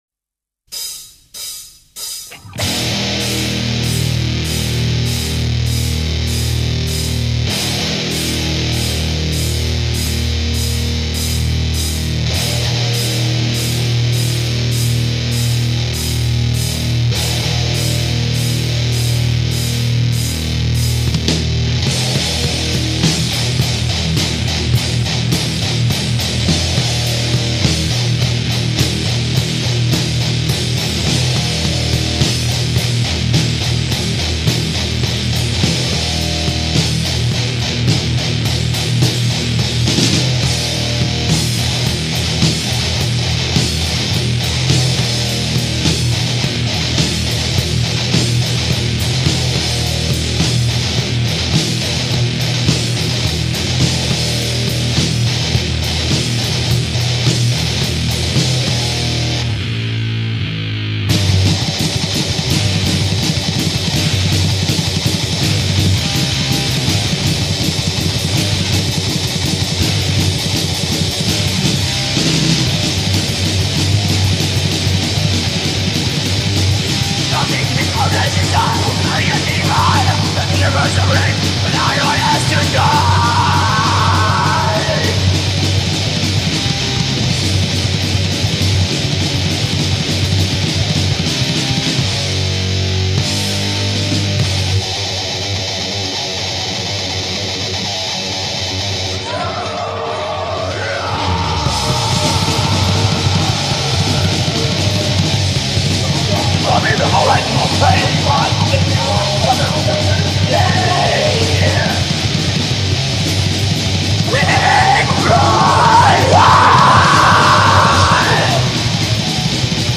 با ووکال تقویت شده
دث متال بلک متال